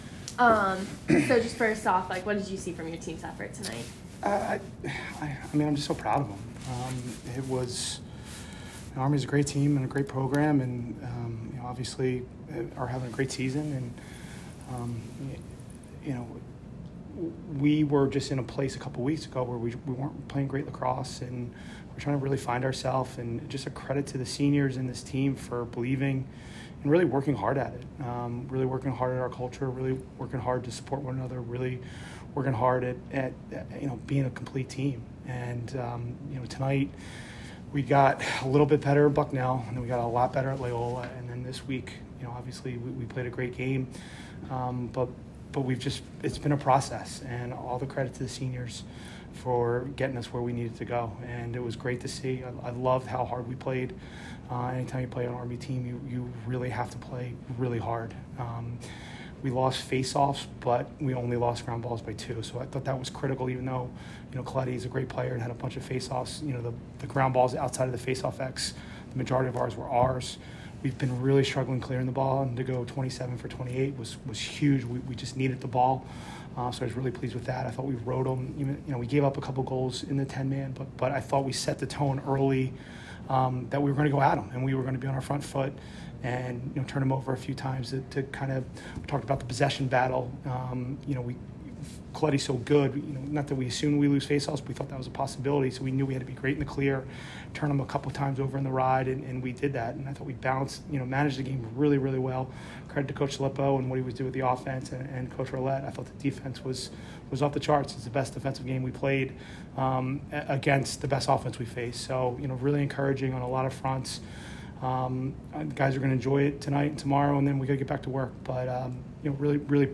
Men's Lacrosse / #1 Army West Point Postgame Interview